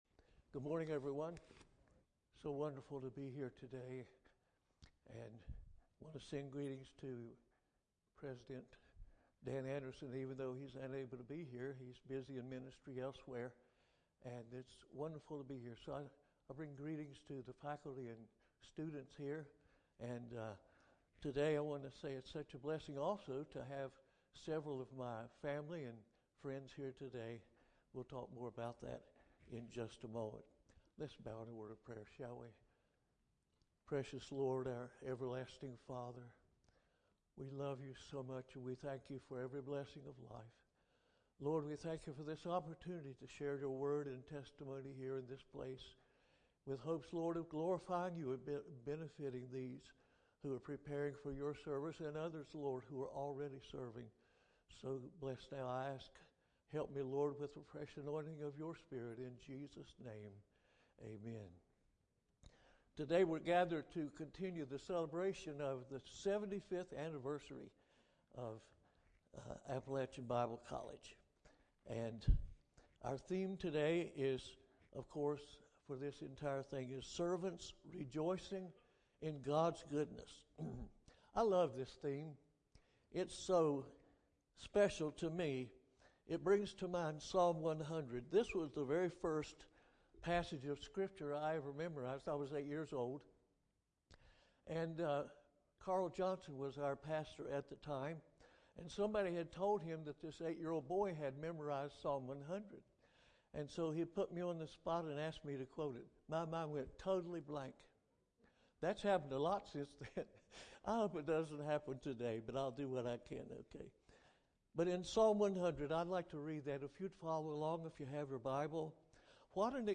Bible sermon audio is available for download, as recorded at Appalachian Bible College chapel services.